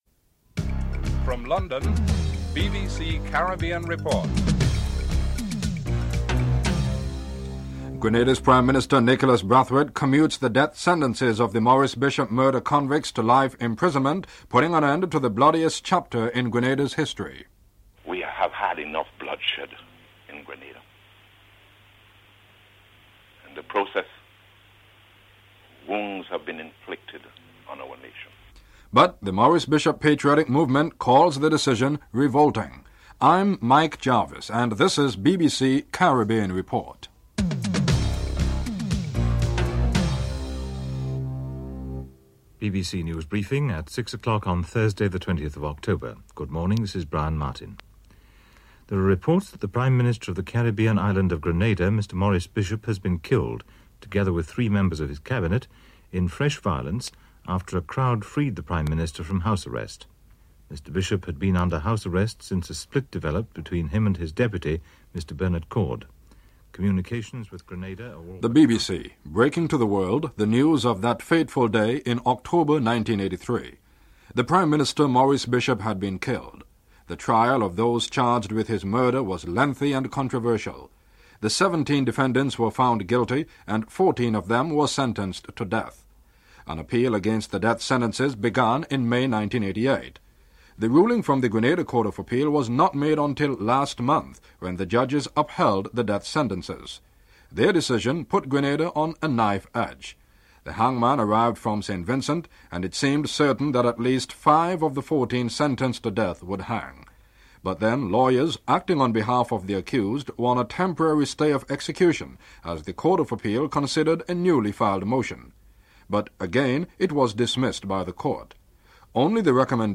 1. Headlines (00:00-00:41)
4. The report concludes with an extract from a speech by the late Maurice Bishop in June 1983 (13:56-14:54)